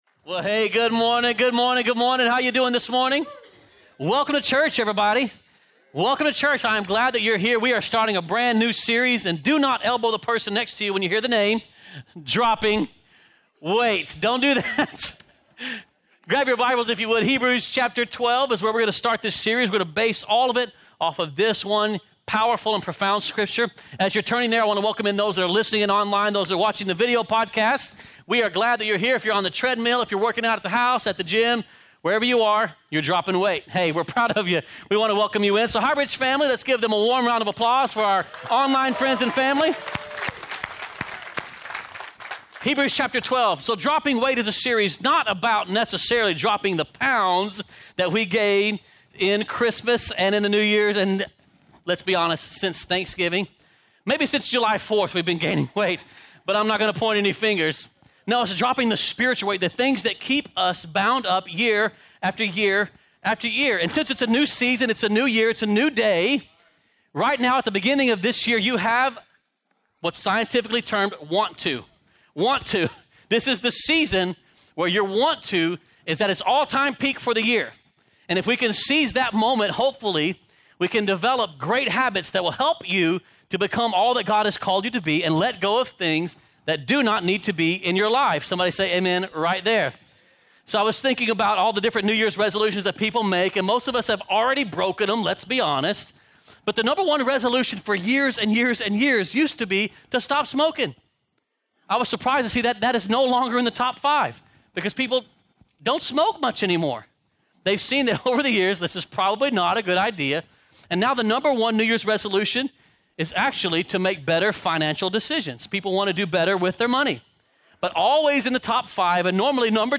2019 Sermon